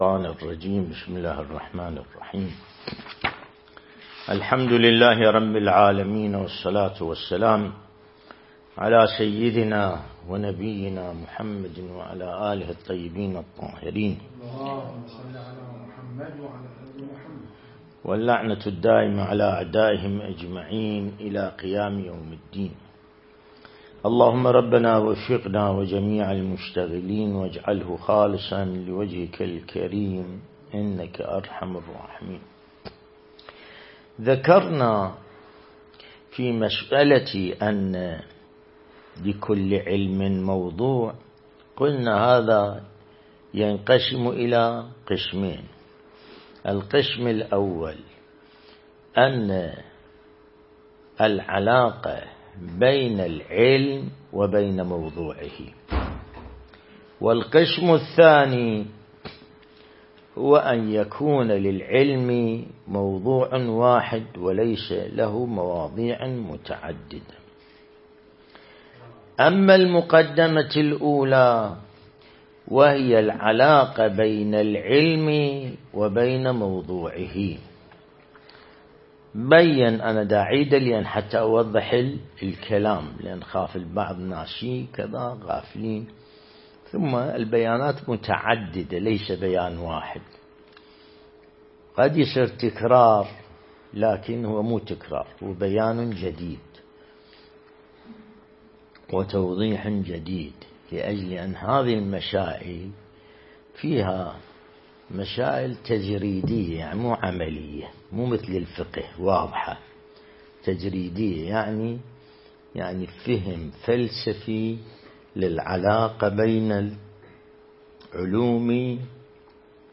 درس البحث الخارج الأصول (32)
النجف الأشرف